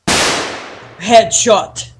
headshot_on35fCg.mp3